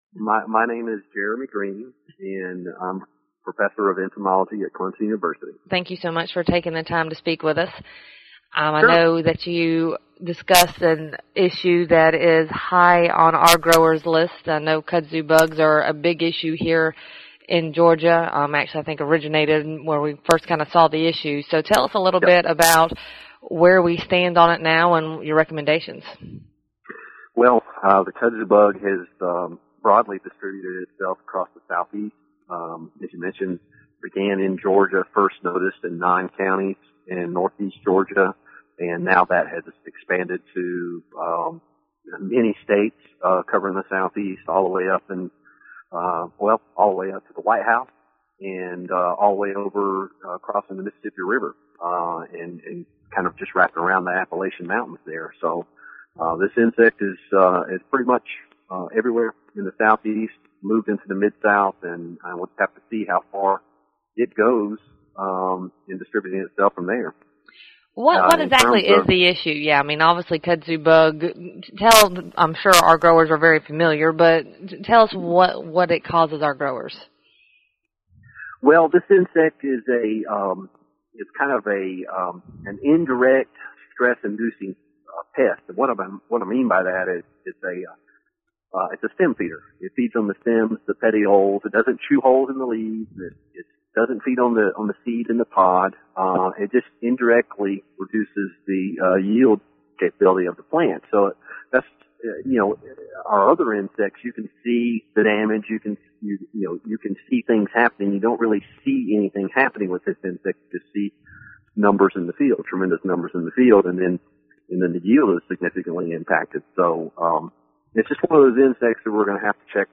The Kudzu Bug is growing concern for our soybean farmers in the southeast and was one of the hot topics discussed during Commodity Classic, being held in San Antonio this week. In the following interview